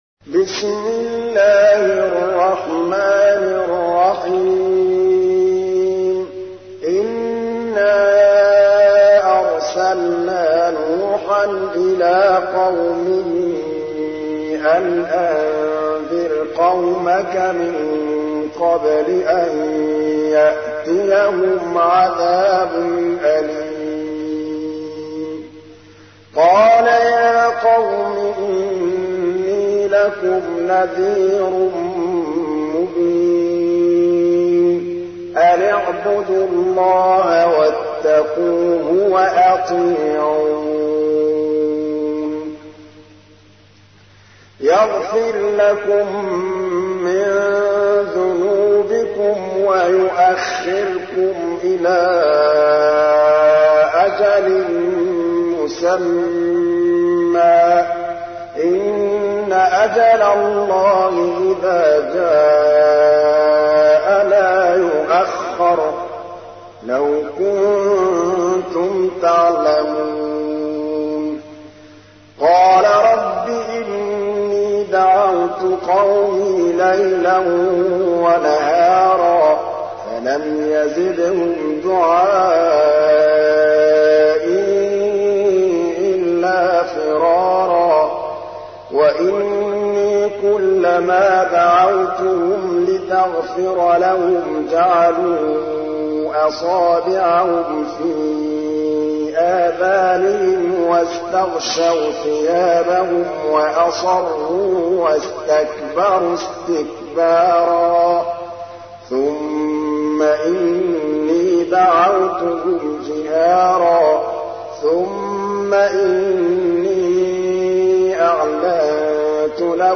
تحميل : 71. سورة نوح / القارئ محمود الطبلاوي / القرآن الكريم / موقع يا حسين